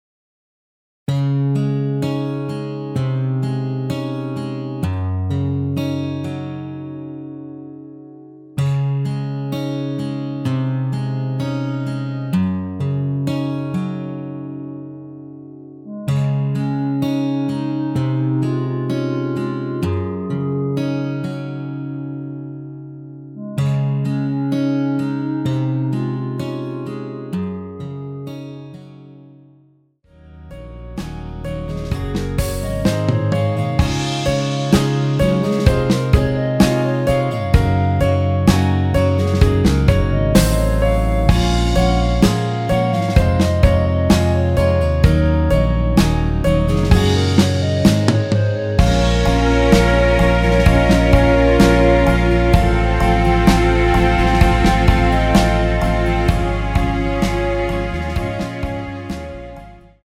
원키에서(-1)내린 멜로디 포함된 MR입니다.
Db
앞부분30초, 뒷부분30초씩 편집해서 올려 드리고 있습니다.